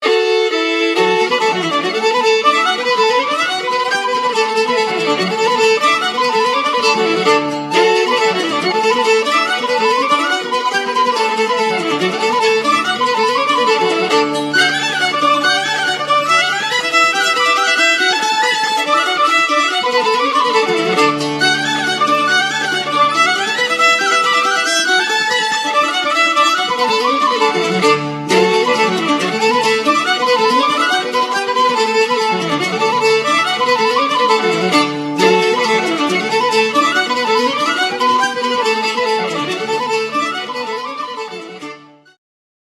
mandolina, bozuki, gitara, bodhran